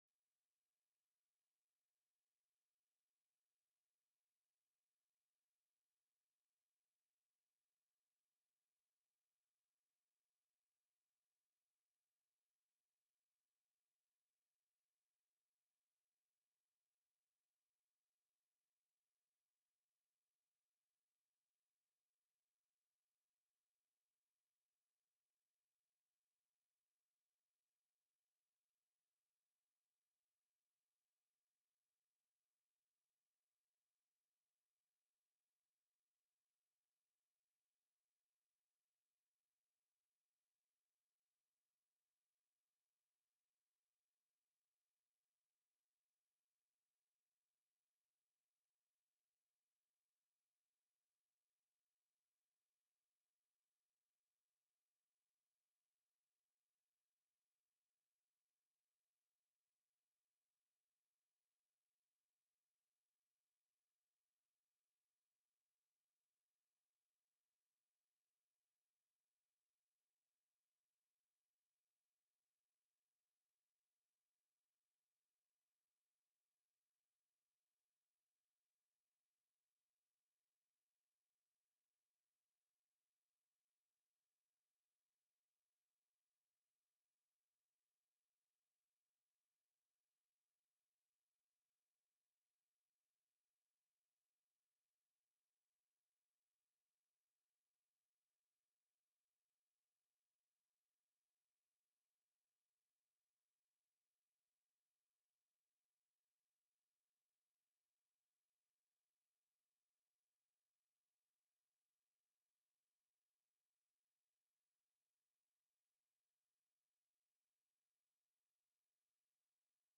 The audio recordings are captured by our records offices as the official record of the meeting and will have more accurate timestamps.
SENATOR FOREST DUNBAR, SPONSOR, provided a review of the bill. The legislation capped payday loan annual percentage rates at 36 percent aligning with the federal cap that already protected active duty service members and their families.